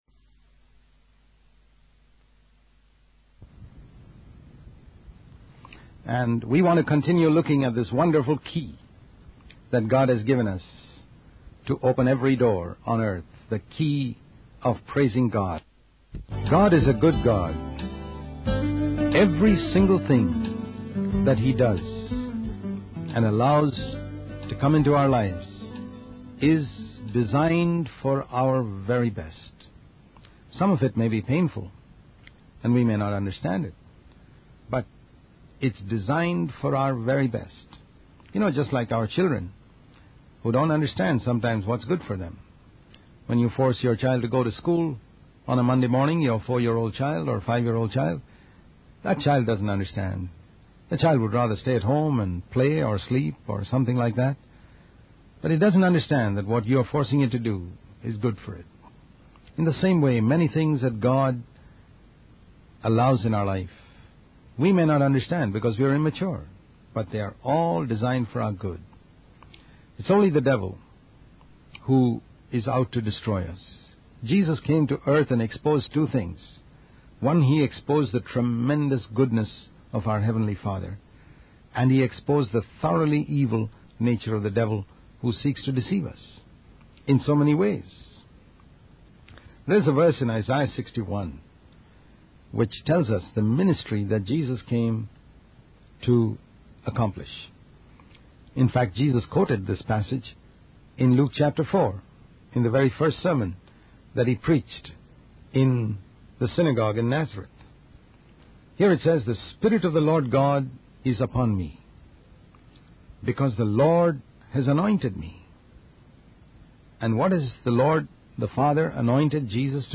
In this sermon, the preacher emphasizes the lack of excitement and joy among Christians in praising God. He highlights the power of praise in silencing the enemy and opening doors in our lives. The preacher also discusses how God's actions and circumstances in our lives are ultimately for our best, even if we don't understand them.